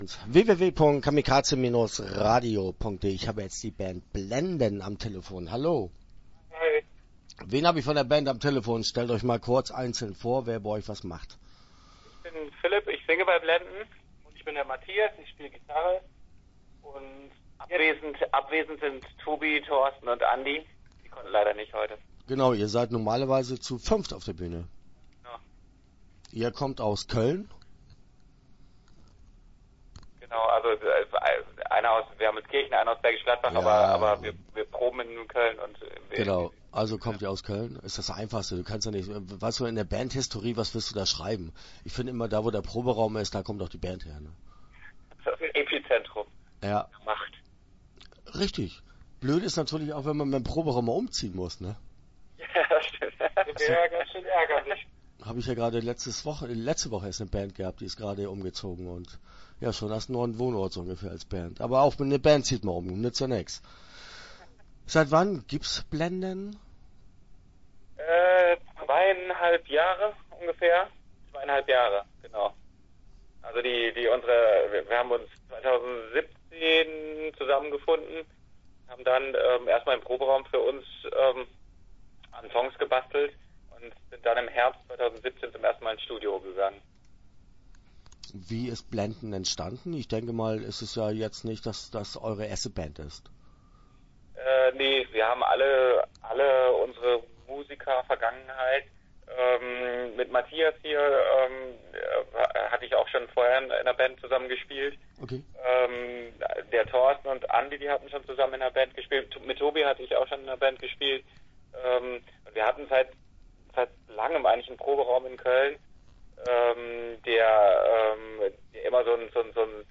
Start » Interviews » BLENDEN